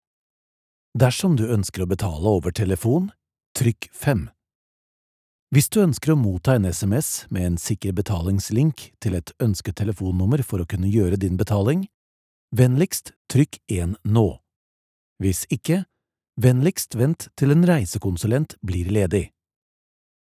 IVR
Adulto joven
Mediana edad
CálidoAutoritarioCreíblePotente